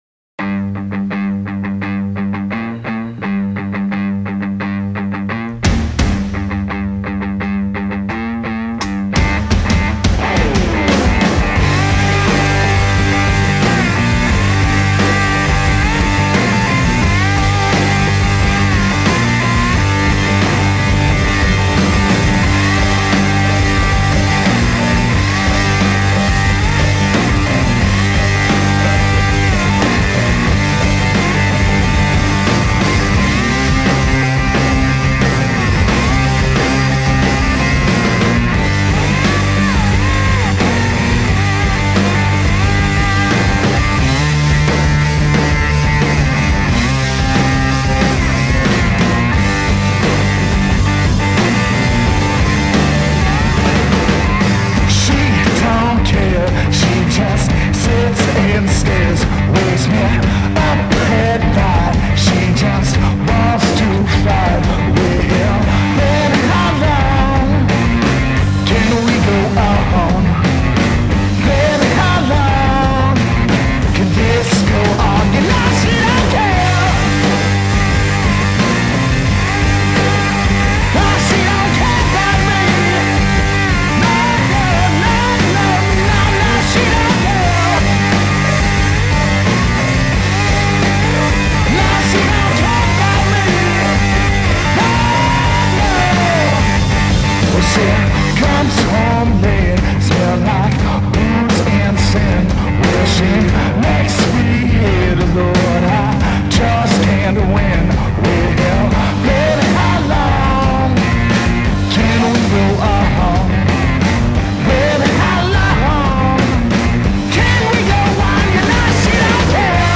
The Blues-driven Rock 'N Roll noise combo
slide guitarist and lead vocalist
bass and skateboard